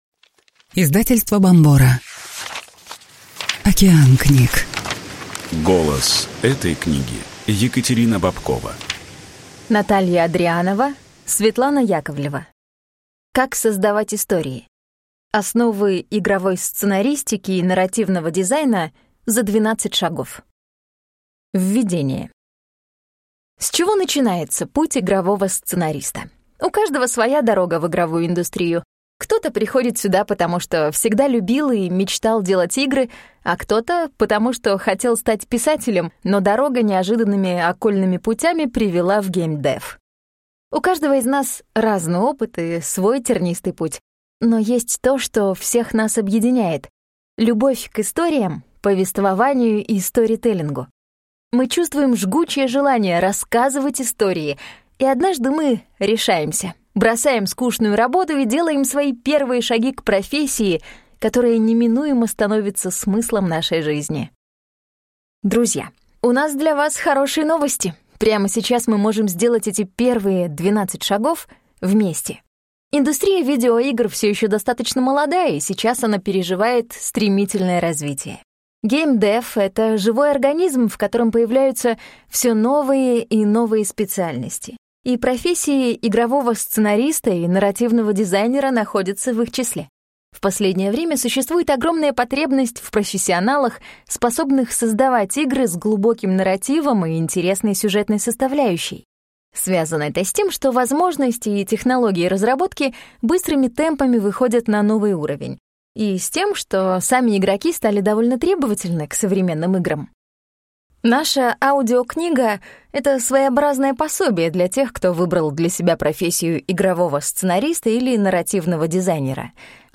Аудиокнига Как создавать истории. Основы игровой сценаристики и нарративного дизайна за 12 шагов | Библиотека аудиокниг